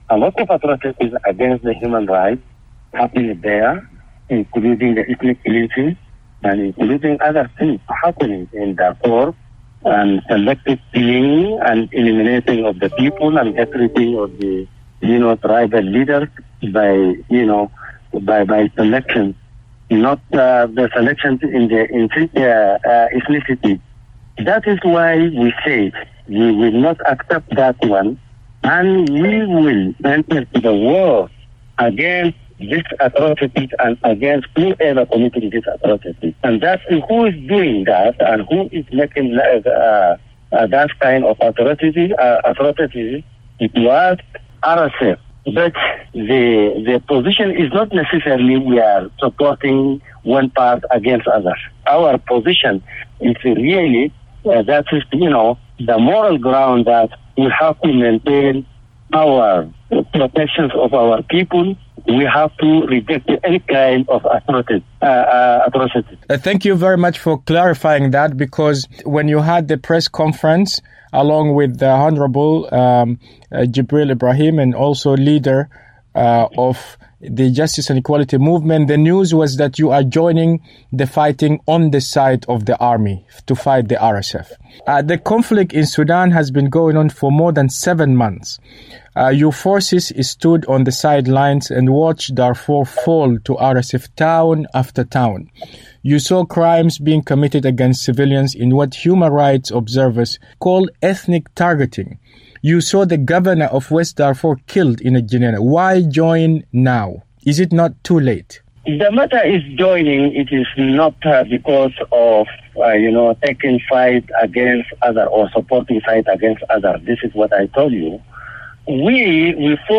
Two Darfur-based armed movements, which were previously observing from the sidelines of Sudan's conflict between the army and the paramilitary Rapid Support Forces, have now chosen to actively engage in combat to prevent atrocities against civilians in Darfur. In an exclusive interview